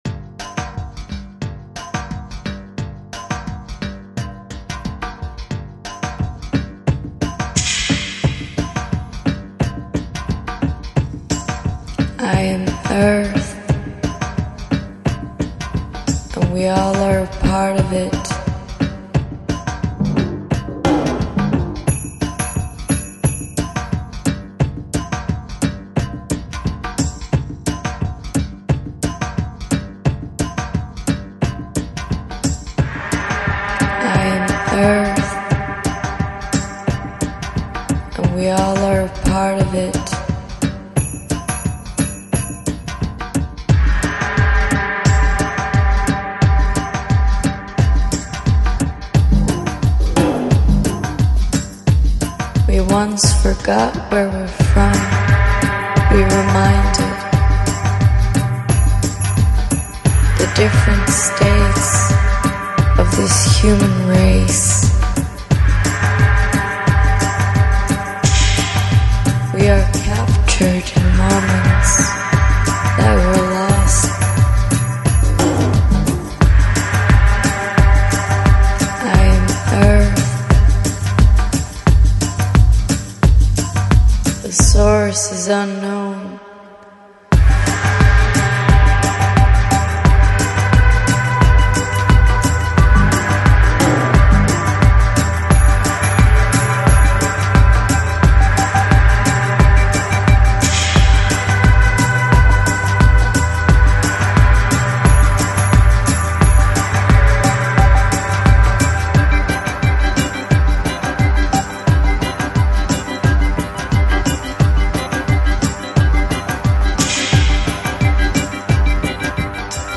Organic House, Deep House Продолжительность